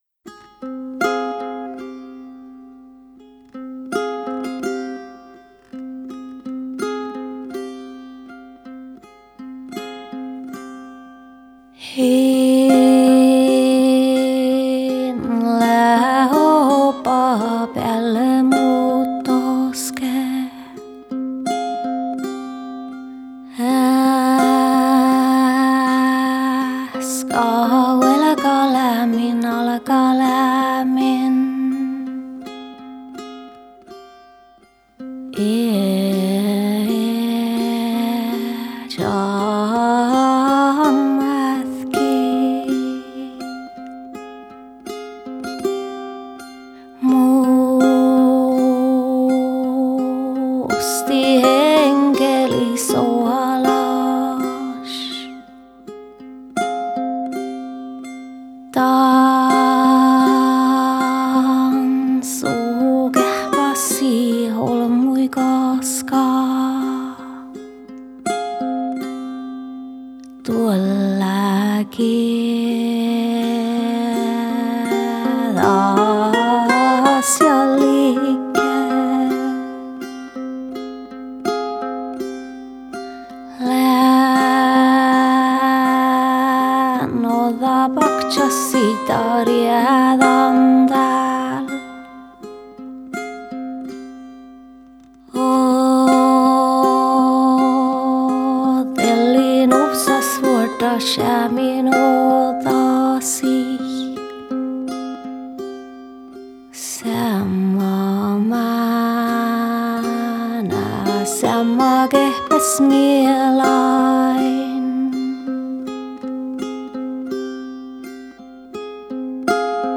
Style: Saami folk, Joik
Stereo